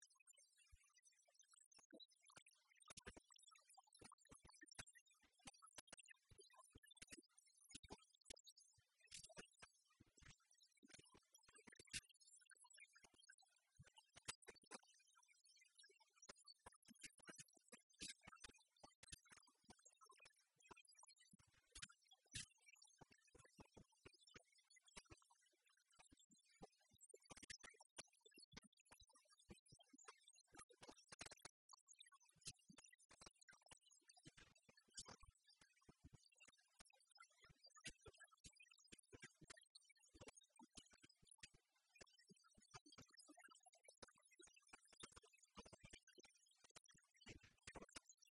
Invitat de Ovidiu Ioanițoaia la Europa FM, în emisiunea “Tribuna Zero”, fostul mijlocaș al Stelei a spus că va intra cu siguranță în turul al 2-lea, iar favorit ar fi, deocamdată, actualul președinte, Răzvan Burleanu: